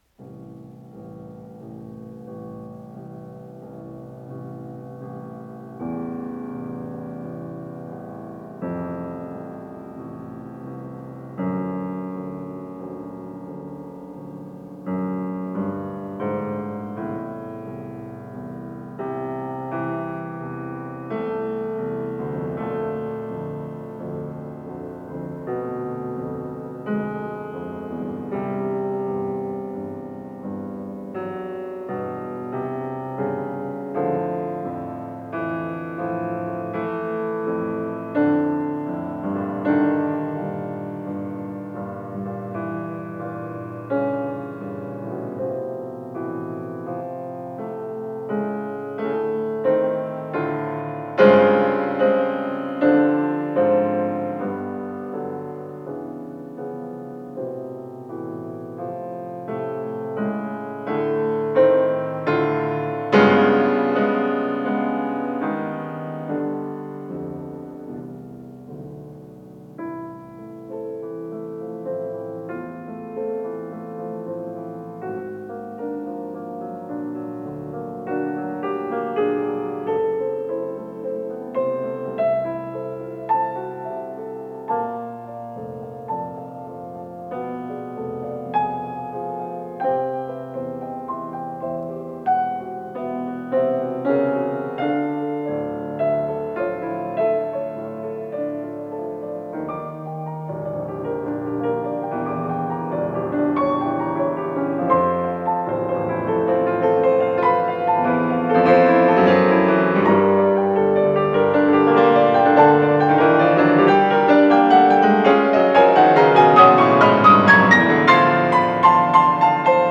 с профессиональной магнитной ленты
Анданте ассаи
ИсполнителиНиколай Петров - фортепиано
ВариантДубль моно